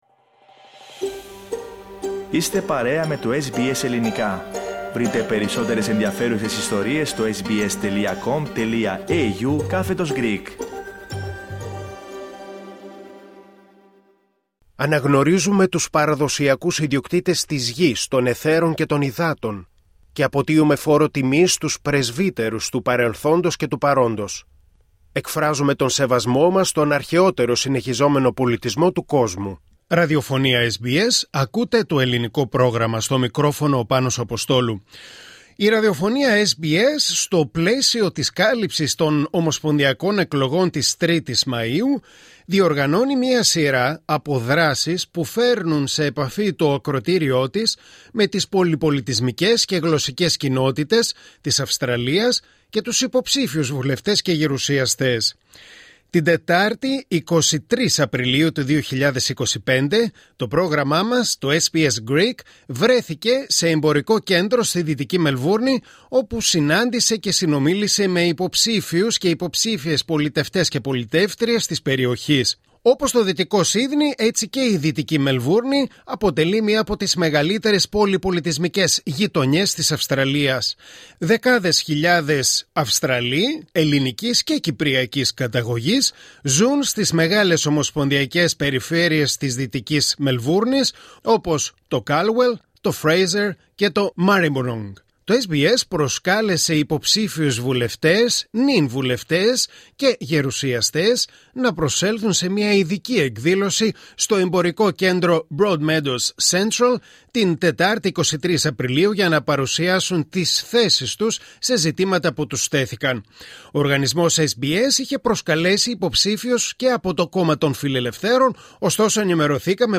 Politician who spoke to SBS Greek during the SBS Election Exchange, Broadmeadows Central, April 2025